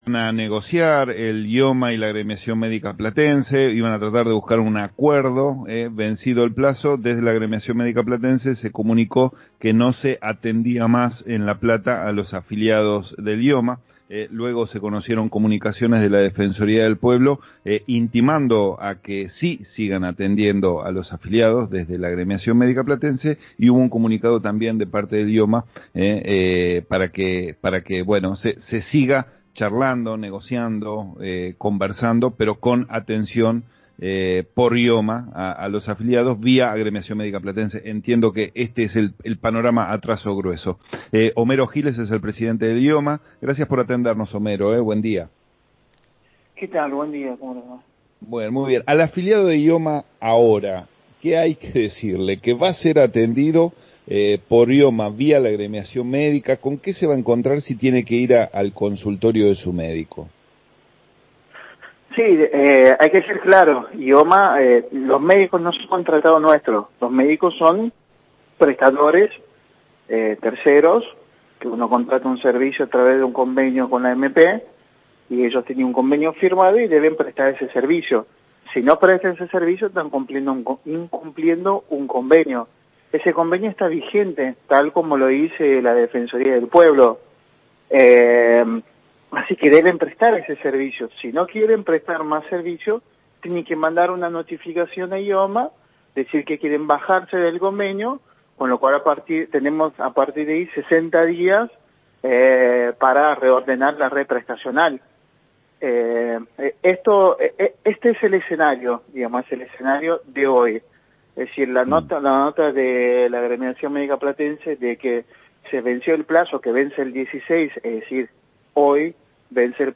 Homero Giles, presidente de IOMA habló en La Cielo, sobre la relación de IOMA con la AMP y el estado del convenio.